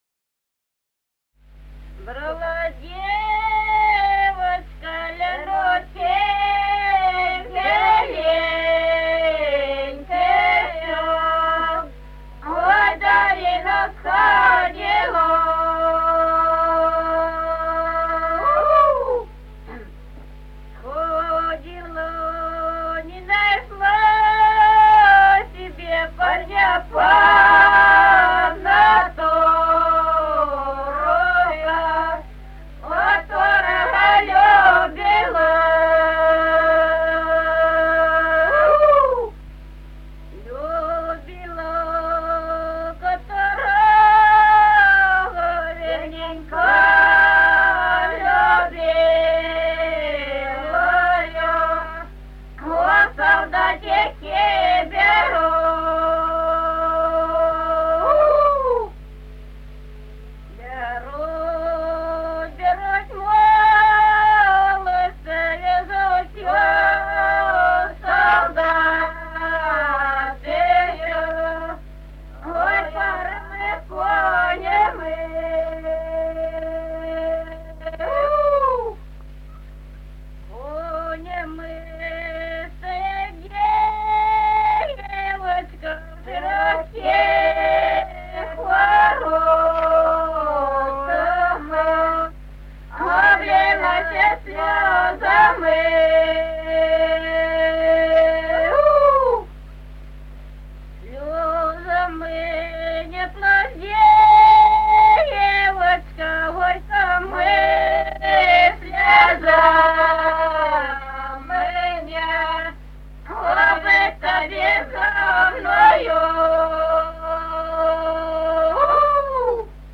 Народные песни Стародубского района «Брала девочка», рекрутская гукальная.
1959 г., с. Чубковичи.